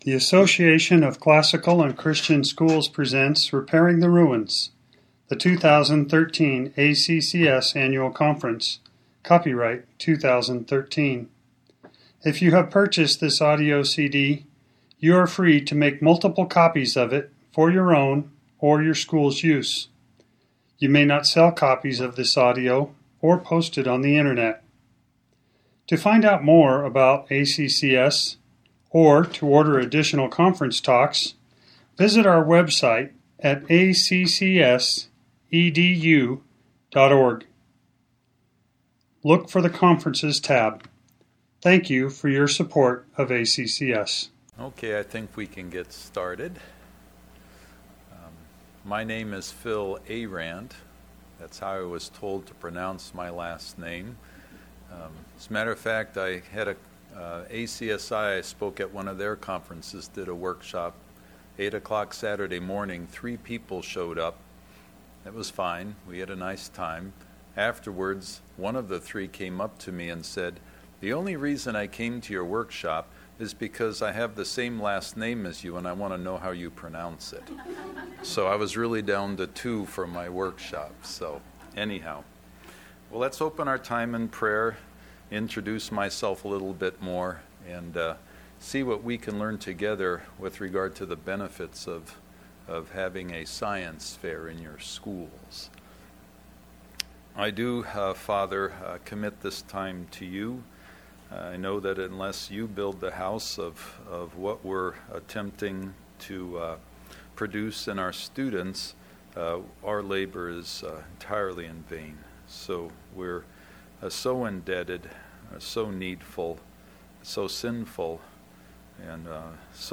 2013 Workshop Talk | 1:00:26 | All Grade Levels, Literature, Science
The Association of Classical & Christian Schools presents Repairing the Ruins, the ACCS annual conference, copyright ACCS.